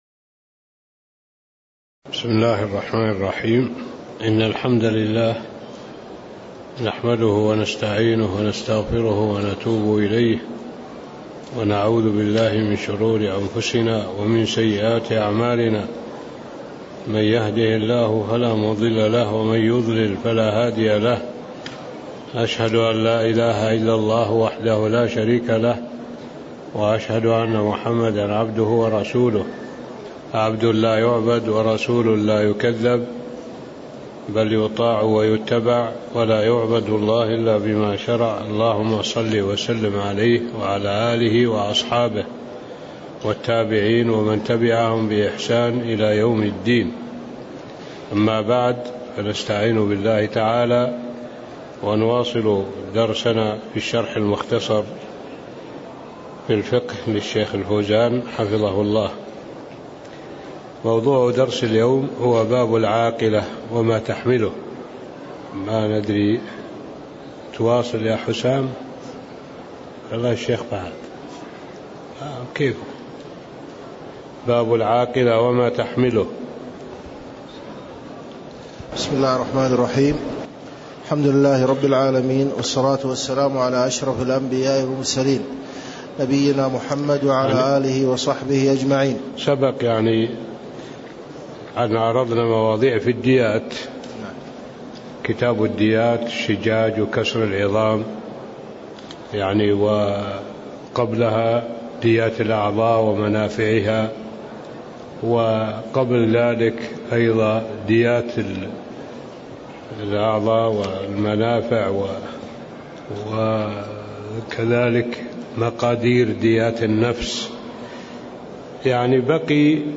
تاريخ النشر ٢١ شوال ١٤٣٥ هـ المكان: المسجد النبوي الشيخ